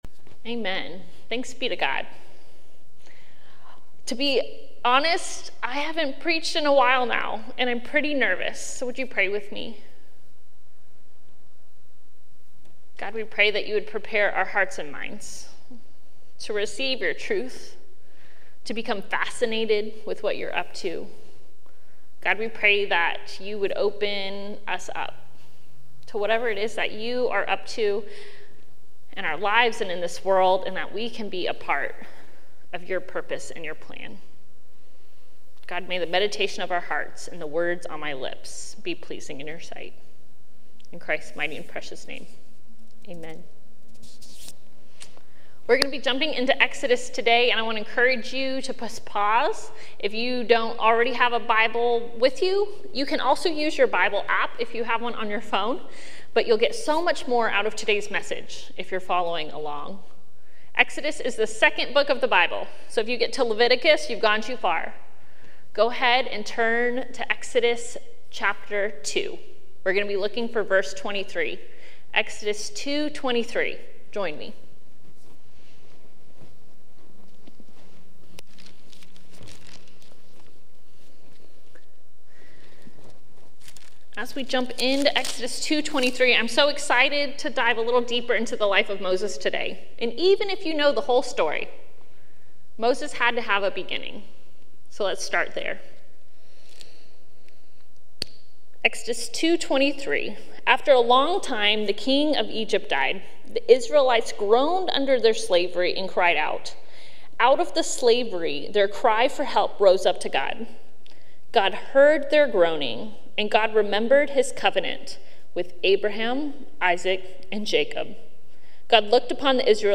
She asks us to ponder: What would we do with a chance to encounter God? Sermon Reflections: What emotions or thoughts arose in you as you listened to the story of Moses and his eventual encounter with the burning bush?